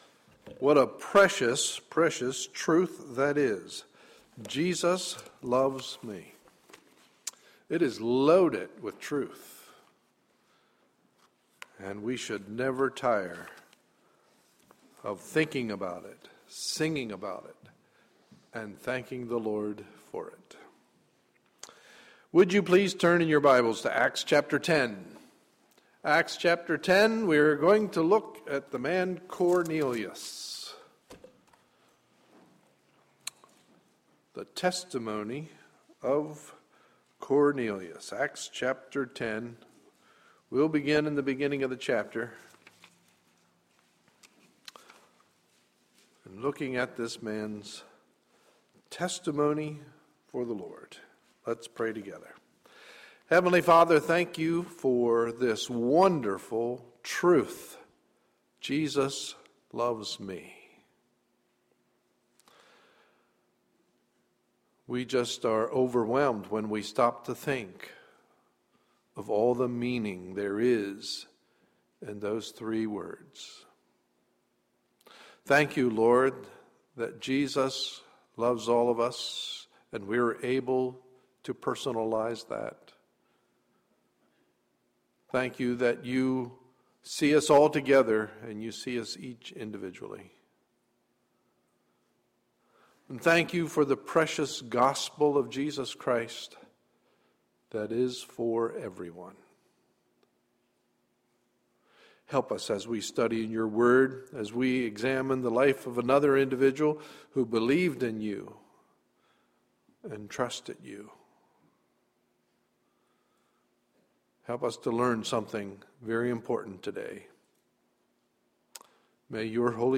Tuesday, September 24, 2013 – Evening Service